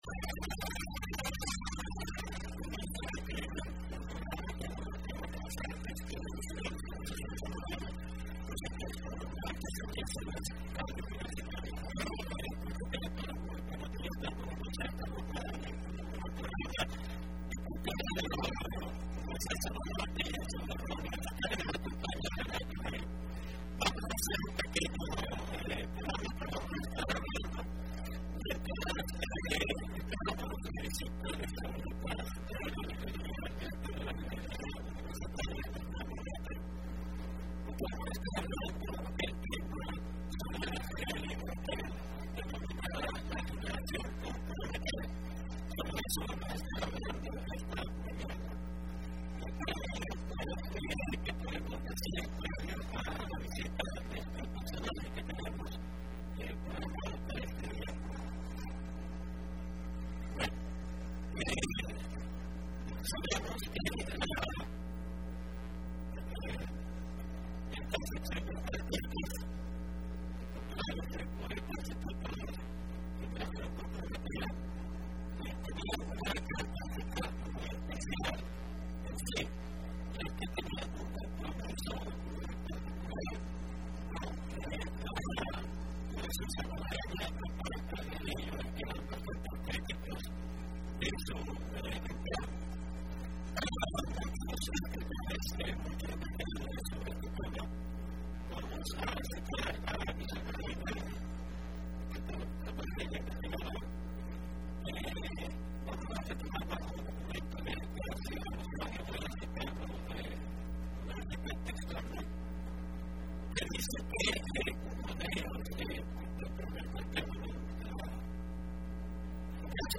Entrevista programa Aequilibrium (12 mayo 2015): La generación comprometida como vanguardia de las letras salvadoreñas y expresión artística cultural